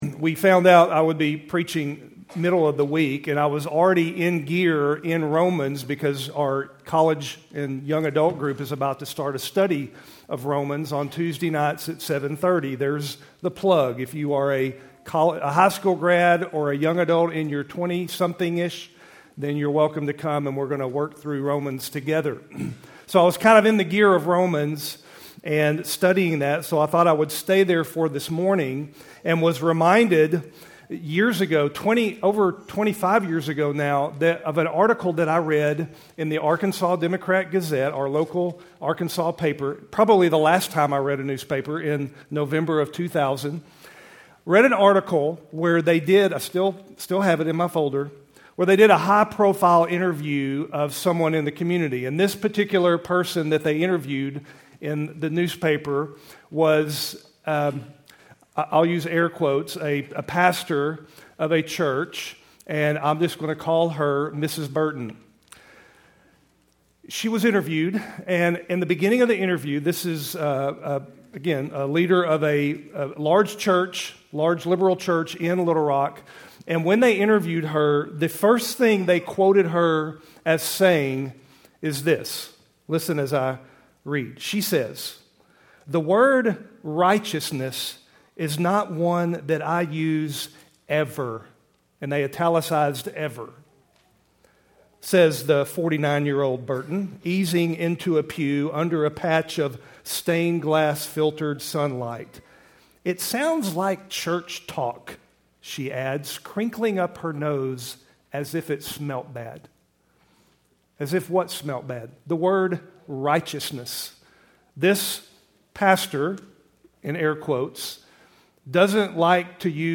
Keltys Worship Service, February 16, 2025.